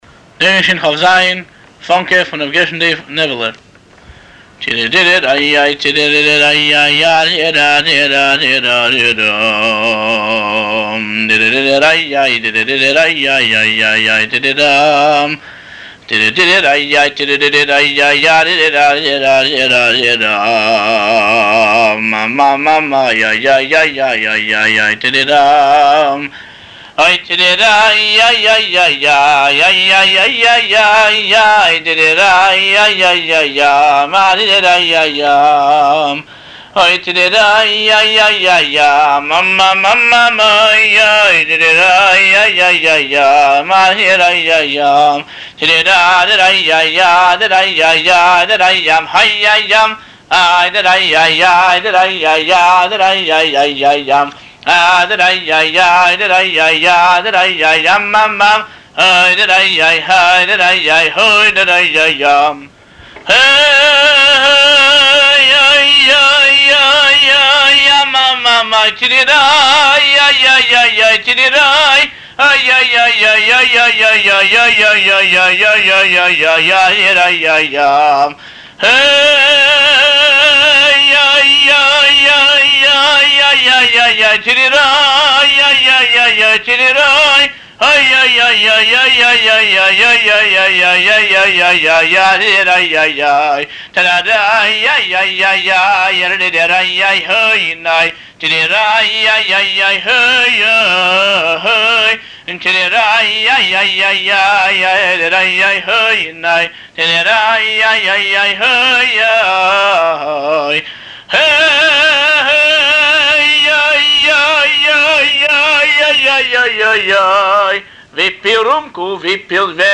לשמיעת הניגון מאת הבעל-מנגן